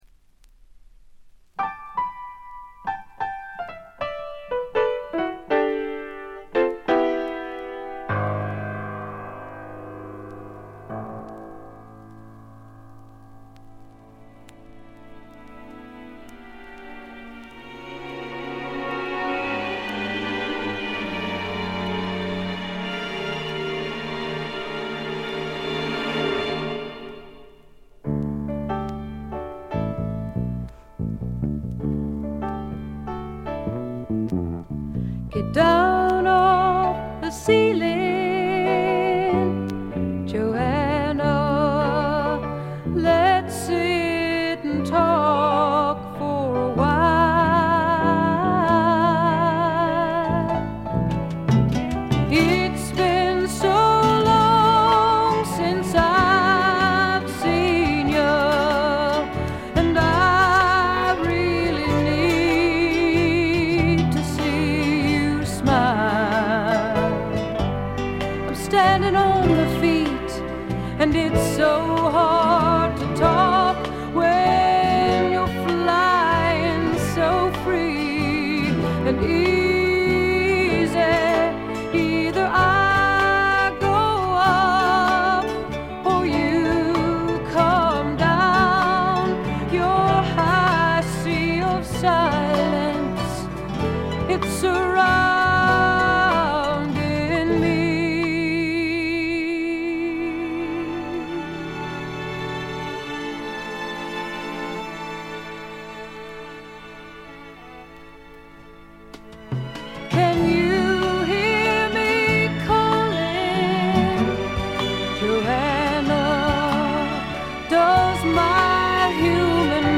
部分試聴ですが軽微なバックグラウンドノイズ、チリプチ程度。
フェミニスト系の女性シンガソングライター
試聴曲は現品からの取り込み音源です。